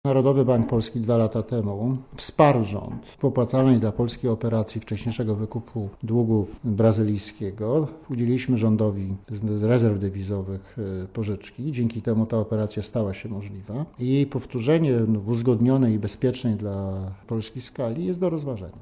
Okazuje się, że Bank Centralny może pomóc Grzegorzowi Kołodce, pożyczając pieniądze na wcześniejszy wykup polskiego długu zagranicznego. Tak przynajmniej wynika z wypowiedzi Leszka Balcerowicza dla Radia Zet.
Mówi Leszek Balcerowicz (150Kb)